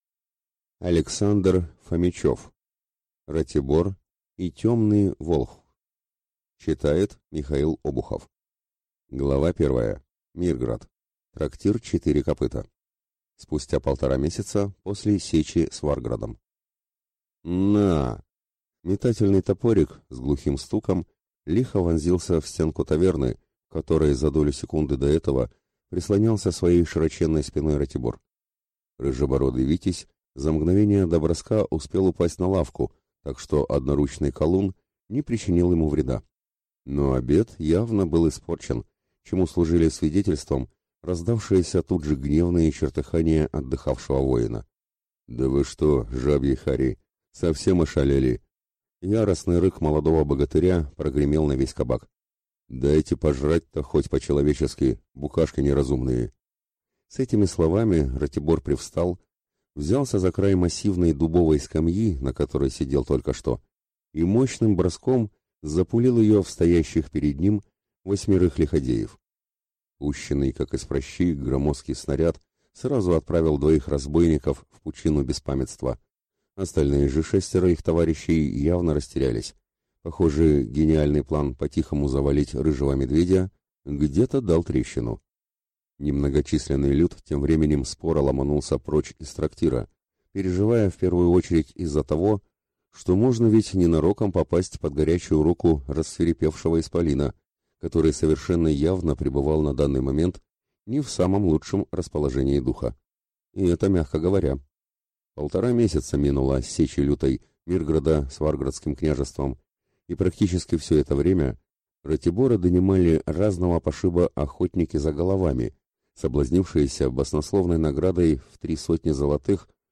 Аудиокнига Ратибор и тёмный волхв | Библиотека аудиокниг